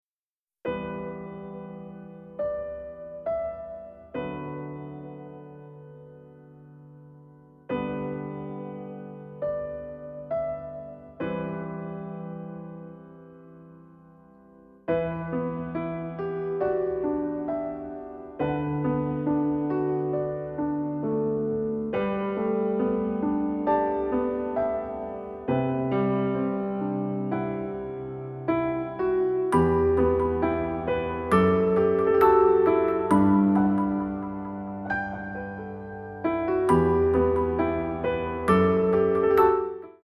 レコーディングスタジオ : JEO